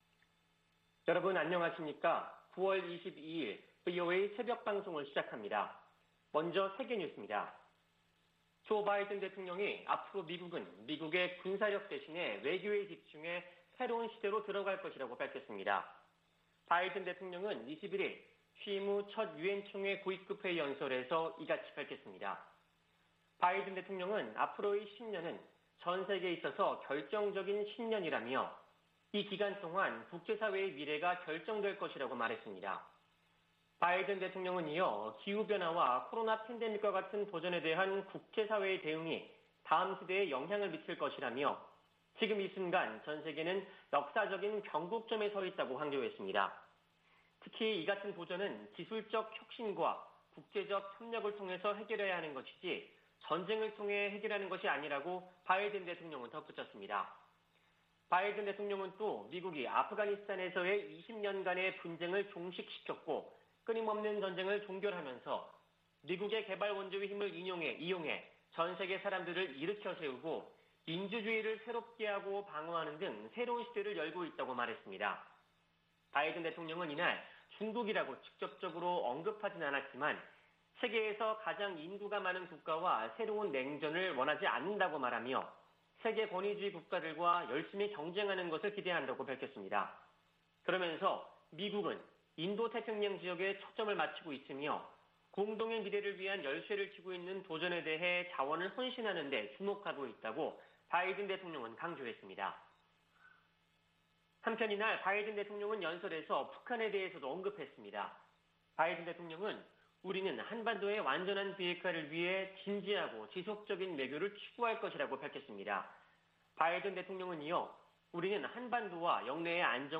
VOA 한국어 '출발 뉴스 쇼', 2021년 9월 22일 방송입니다. 북한이 우라늄 농축 등 핵 프로그램에 전력을 기울이고 있다고 국제원자력기구 사무총장이 밝혔습니다. 미 국무부는 북한의 최근 핵 관련 움직임과 탄도미사일 발사가 유엔 안보리 결의 위반이며 한국과 일본 등에 위협이라고 지적했습니다.